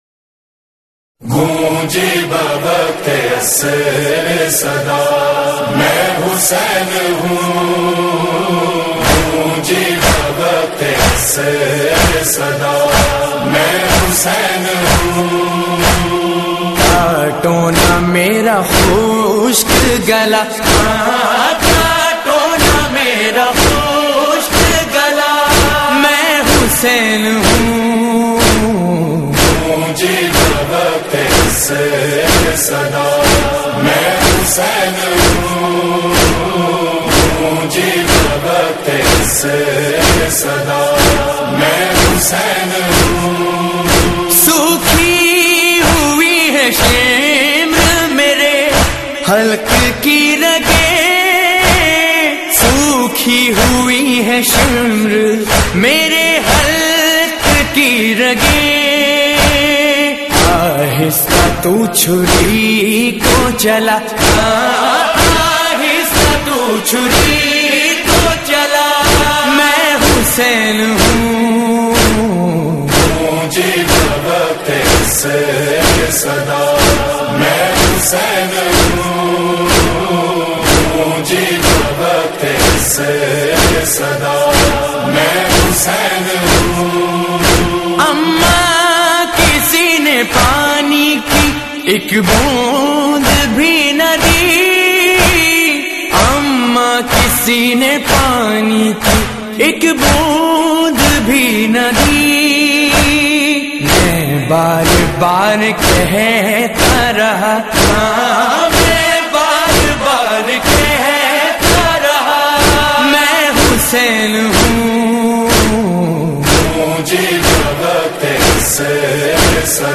All Nohay